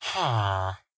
minecraft / sounds / mob / villager / idle2.ogg